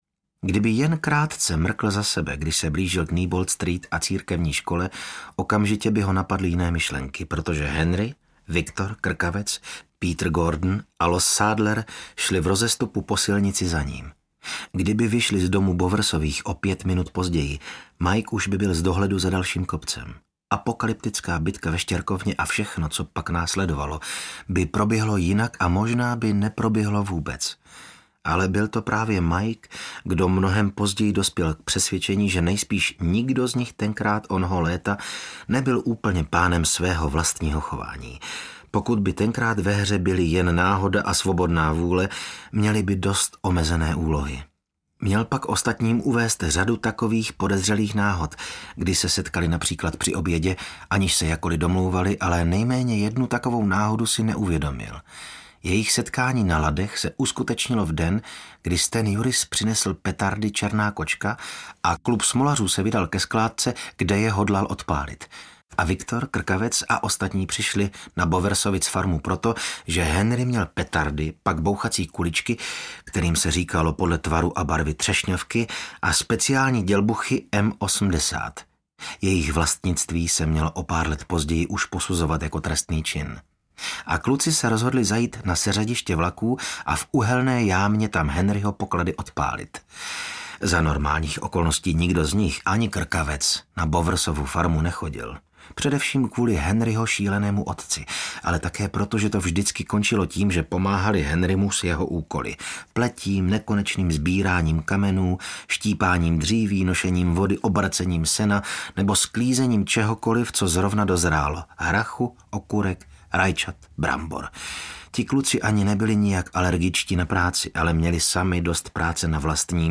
Délka: 2 h 18 min Interpret: Miroslav Krobot Vydavatel: Planet Dark Vydáno: 2022 Série: Stopy hrůzy díl 3 Jazyk: český Typ souboru: MP3 Velikost: 130 MB